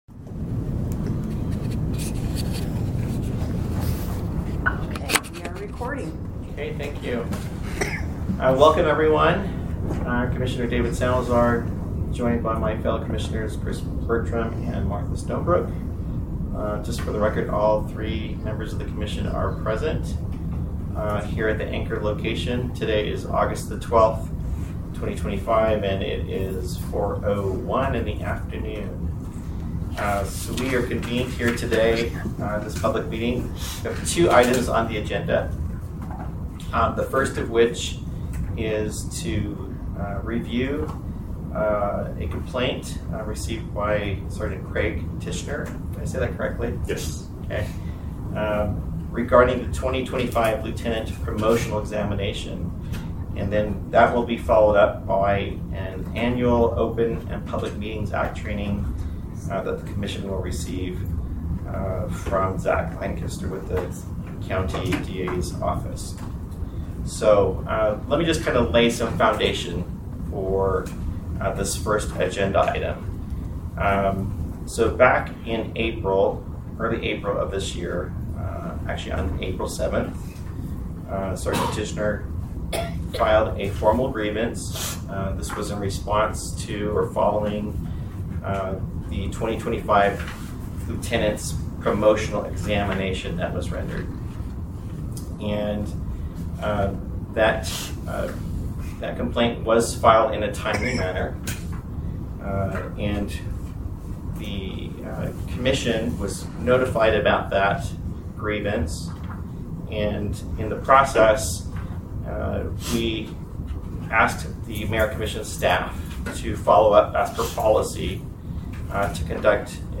Peace Officer Merit Commission Public Meetings and Recordings recording